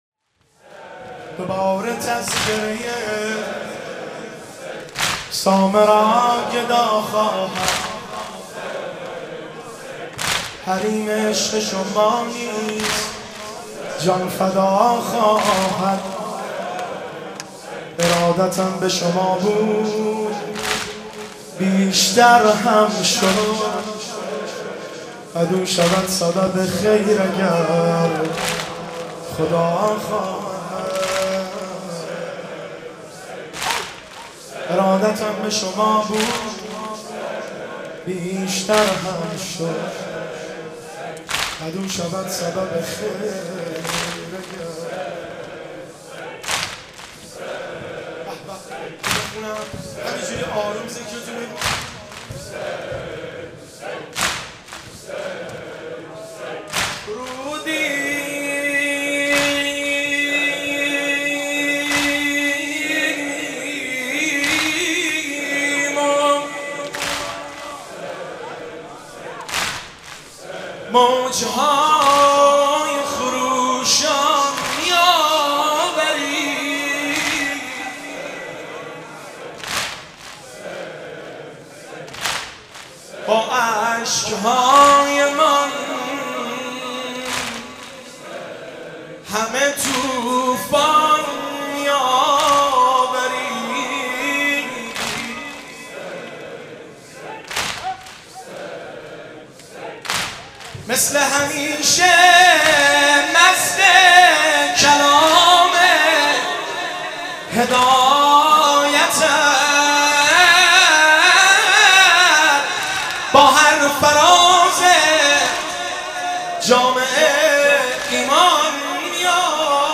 زمزمه - شهادت امام علی النقی الهادی (ع) 1393
هیئت بین الحرمین طهران